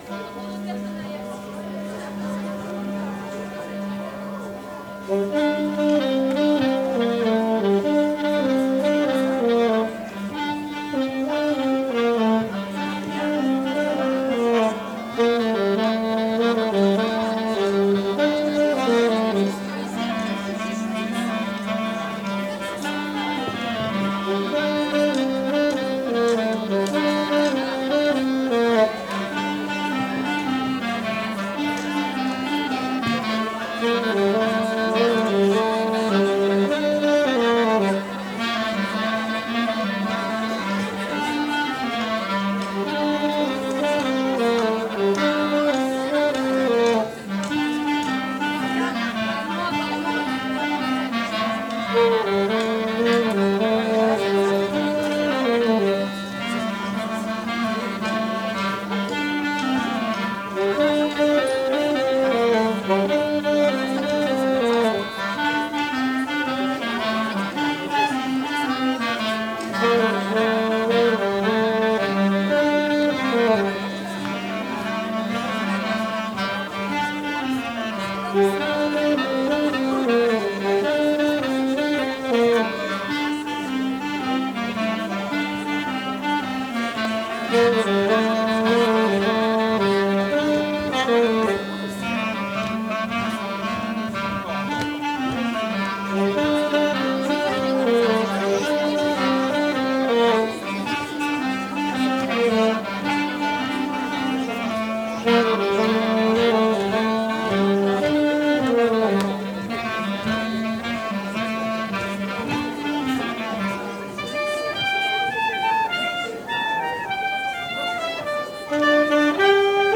02_bal_paludier-clarinettes.mp3